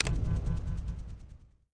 Block Fan Toggle Sound Effect
block-fan-toggle.mp3